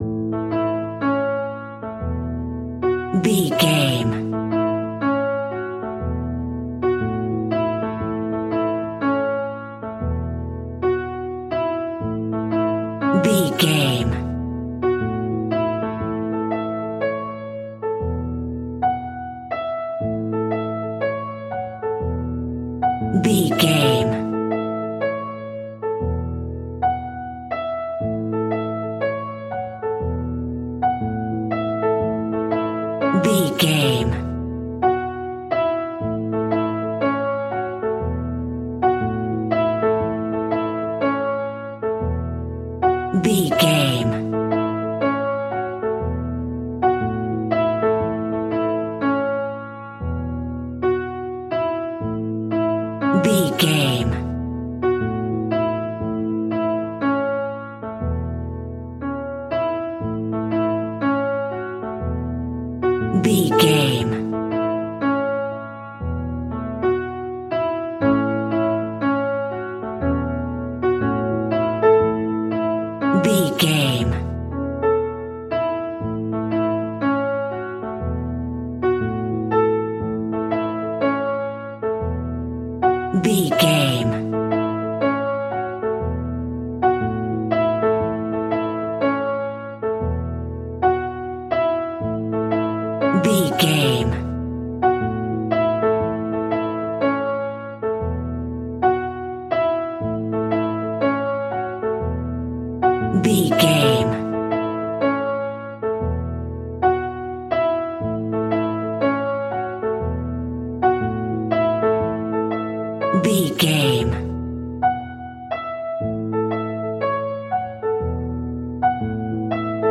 royalty free music
Uplifting
Ionian/Major
Slow
kids music
instrumentals
fun
childlike
cute
happy
kids piano